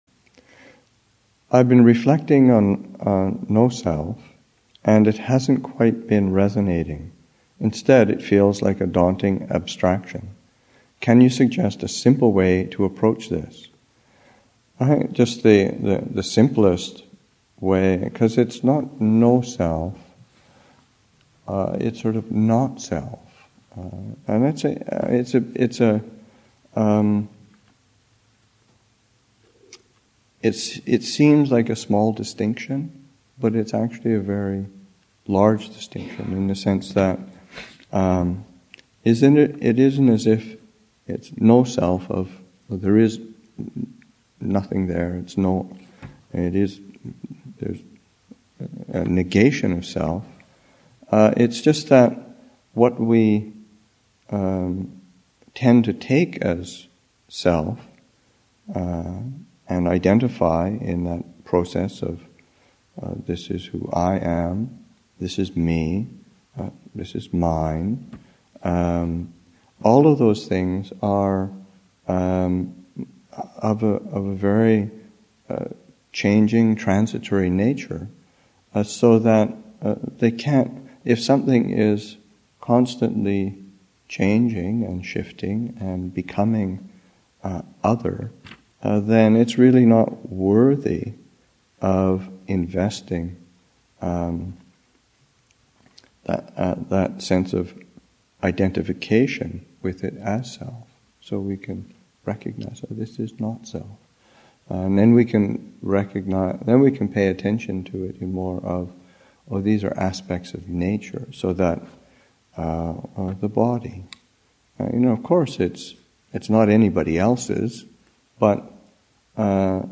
Abhayagiri Monastic Retreat 2013, Session 5, Excerpt 4